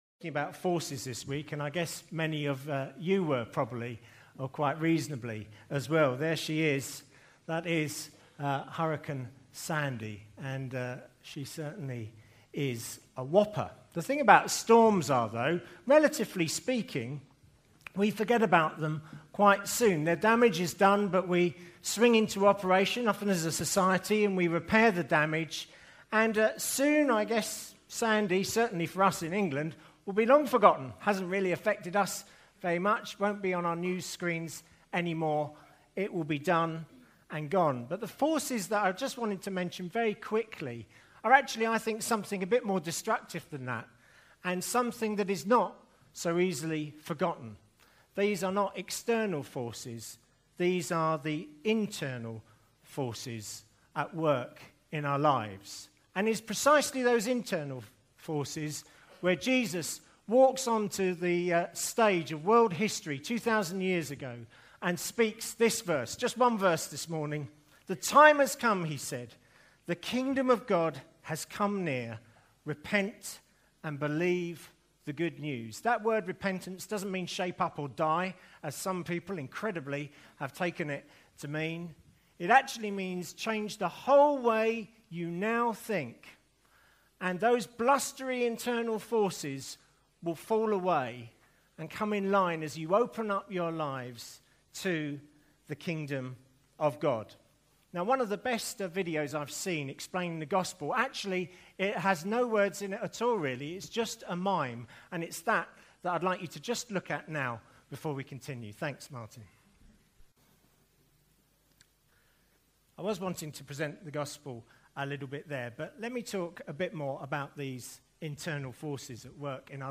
Baptism Service (Mark 1:15)